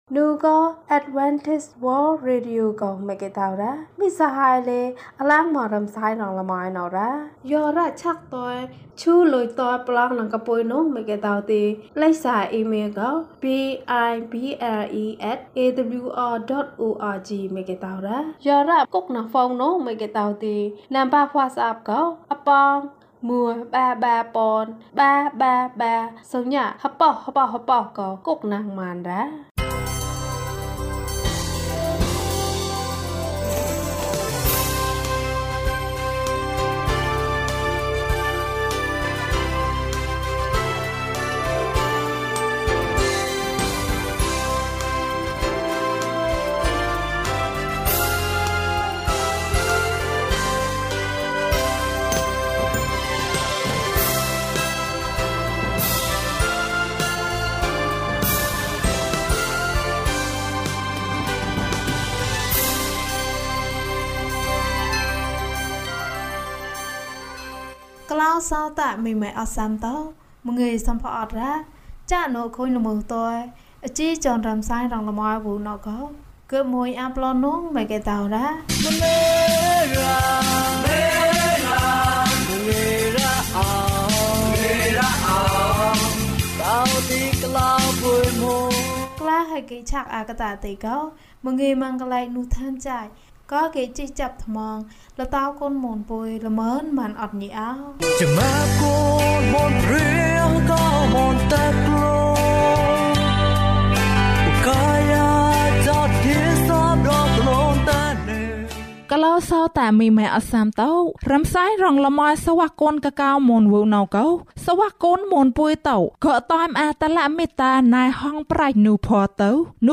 ဖန်တီးမှု။၀၁ ကျန်းမာခြင်းအကြောင်းအရာ။ ဓမ္မသီချင်း။ တရားဒေသနာ။